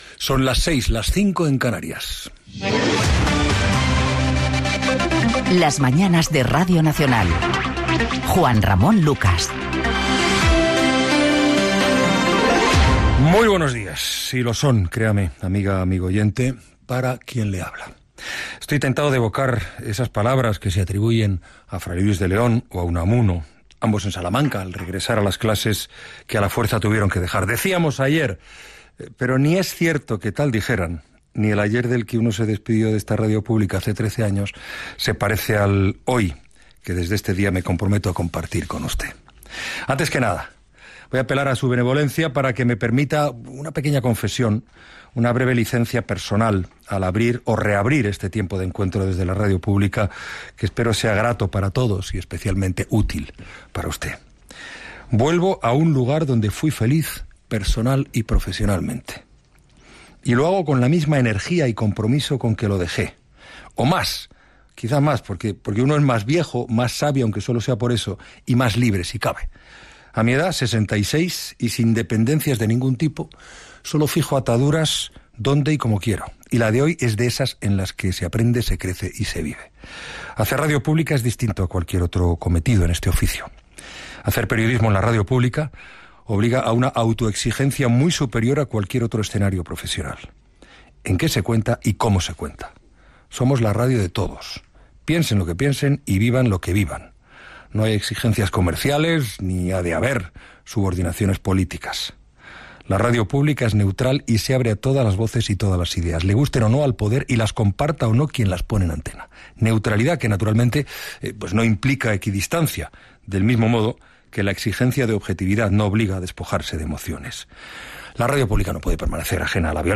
Primera edició del programa presentat per Juan Ramón Lucas. Indicatiu del programa, paraules del presentador que torna a RNE després de 13 anys.
Info-entreteniment